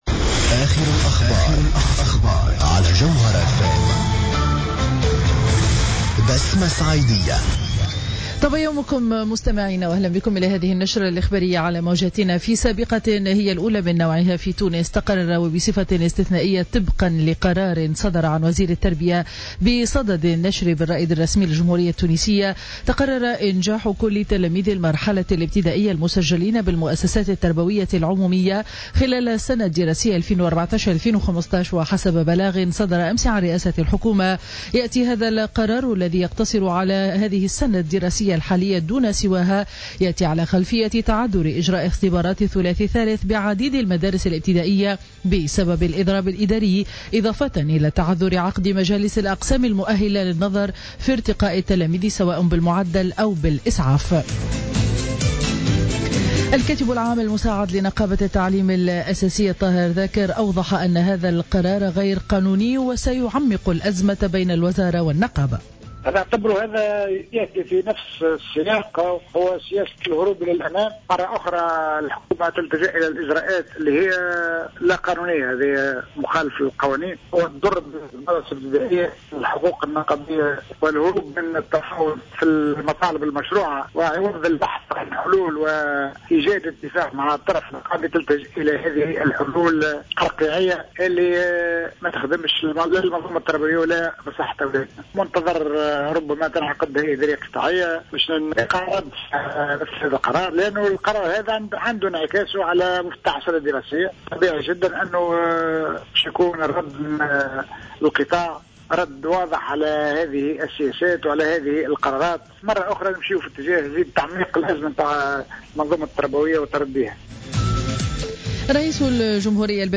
نشرة أخبار السابعة صباحا ليوم الجمعة 12 جوان 2015